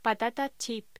Locución: Patata chip
voz